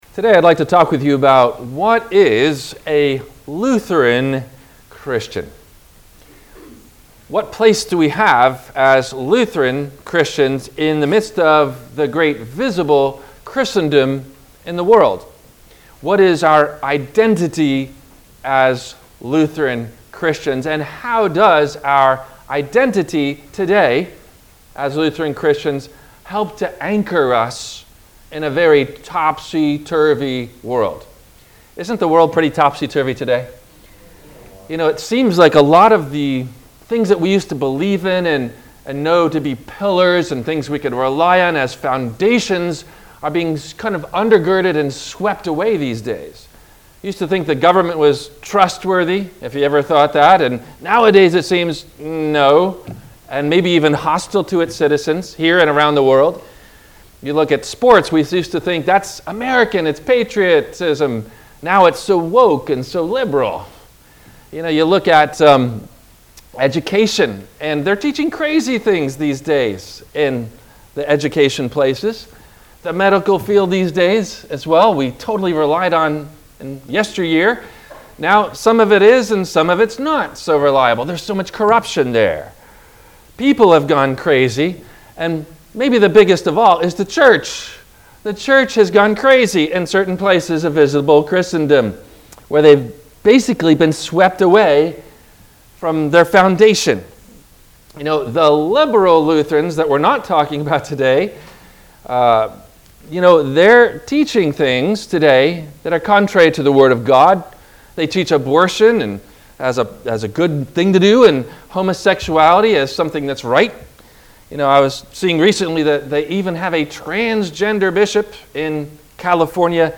WMIE Radio – Christ Lutheran Church, Cape Canaveral on Mondays from 12:30 – 1:00